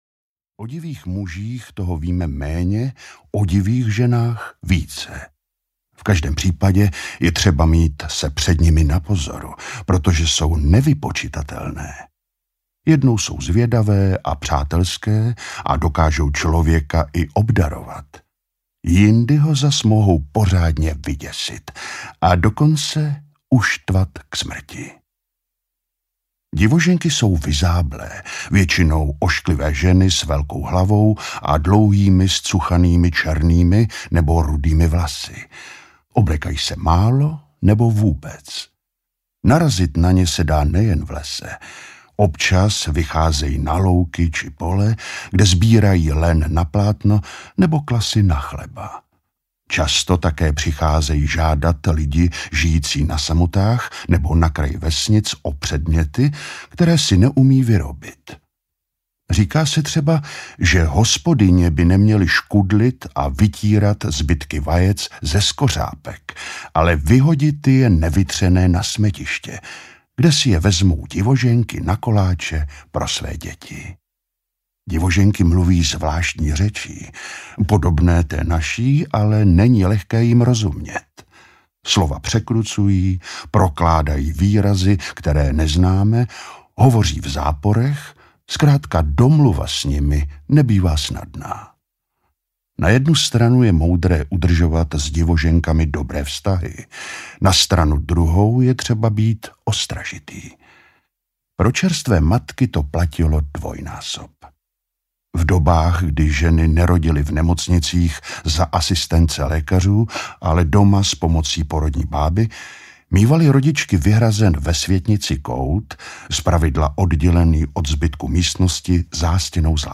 Česká démonologie audiokniha
Ukázka z knihy